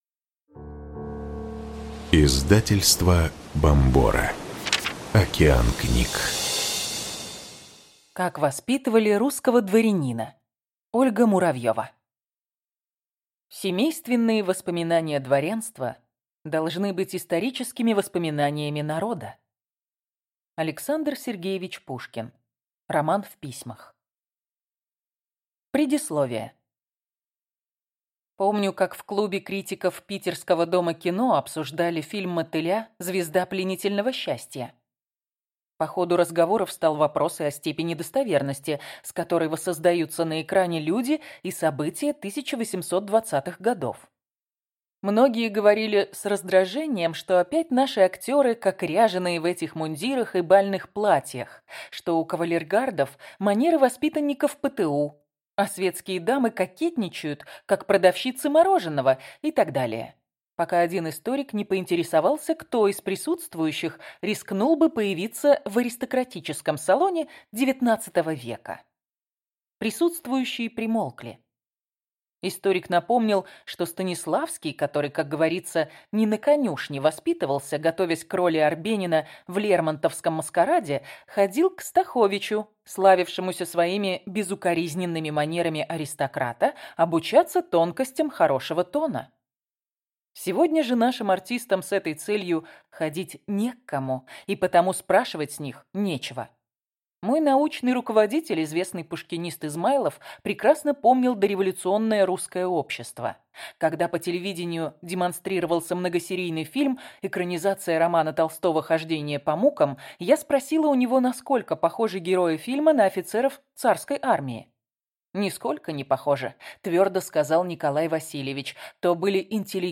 Аудиокнига Как воспитывали русского дворянина. Опыт знаменитых семей России – современным родителям | Библиотека аудиокниг